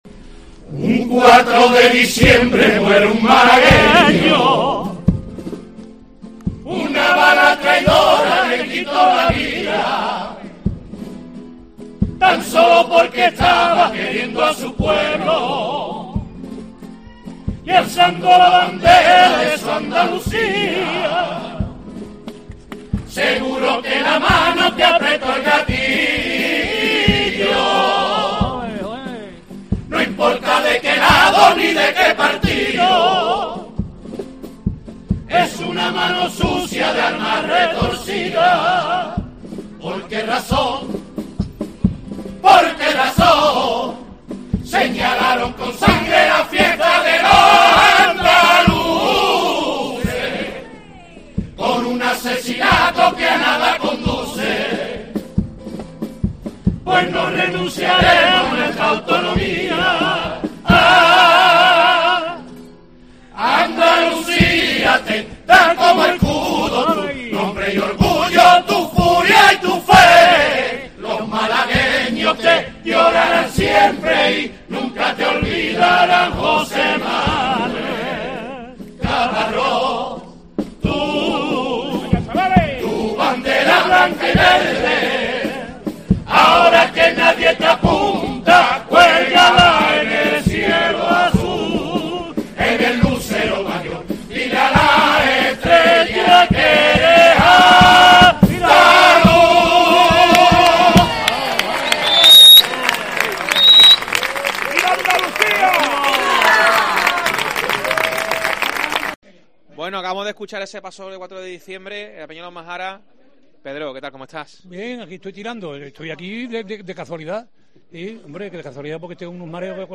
Desde la Peña de Los Majaras en El Puerto de Santa María vivimos el pasodoble de Raza Mora del 4 de diciembre